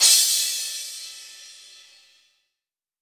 • Big Room Crash Cymbal Sample C# Key 05.wav
Royality free crash single shot tuned to the C# note. Loudest frequency: 6456Hz
big-room-crash-cymbal-sample-c-sharp-key-05-3Eg.wav